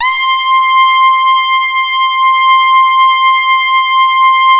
Index of /90_sSampleCDs/InVision Interactive - Keith Emerson - The Most Dangerous Synth and Organ/ORGAN+SYNTH4
84-TARKUS C5.wav